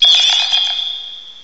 cry_not_cryogonal.aif